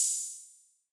Open Hats
Metro Openhats [Perfect].wav